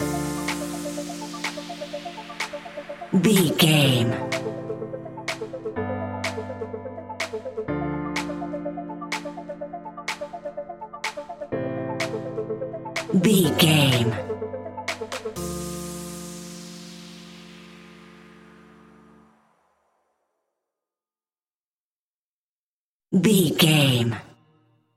Aeolian/Minor
energetic
uplifting
hypnotic
funky
groovy
drum machine
synthesiser
electro house
synth leads
synth bass